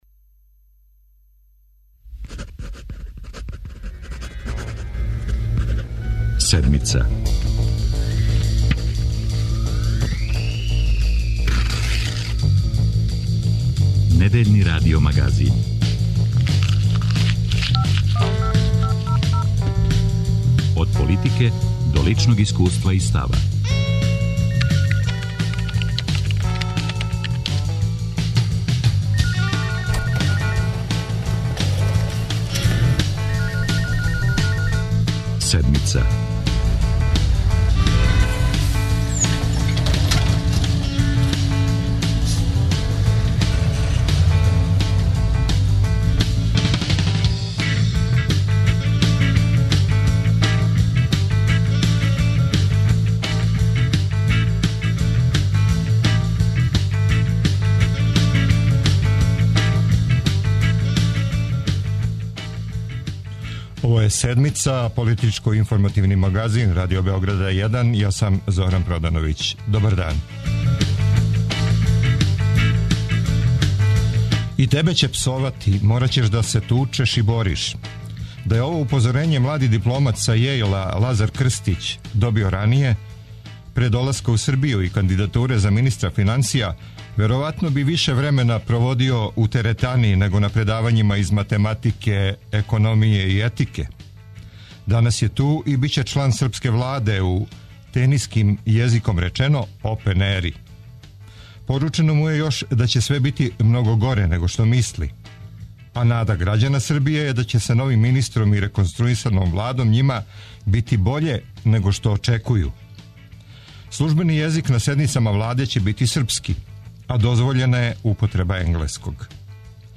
О реконструисаној Влади и изгледима да буде боља за Седмицу говори Расим Љајић.